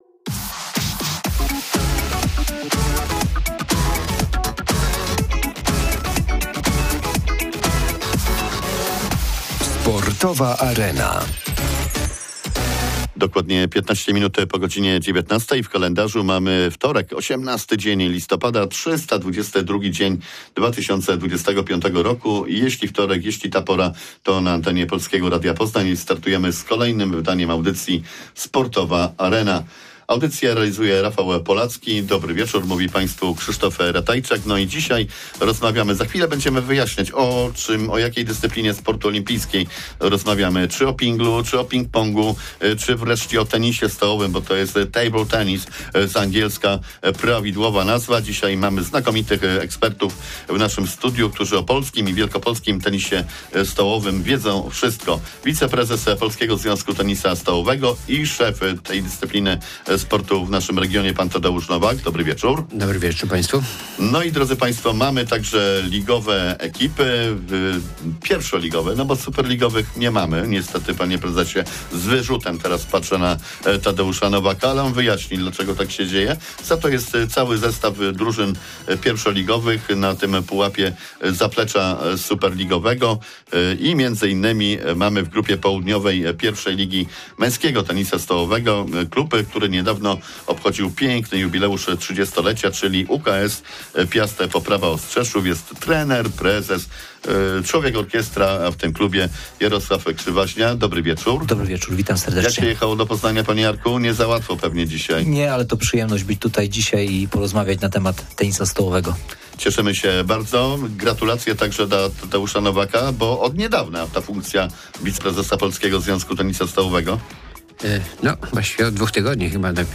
Sportowa Arena-emisja live 18 listopada 2025, godz. 19.15. O polskim i wielkopolskim tenisie stoółowym.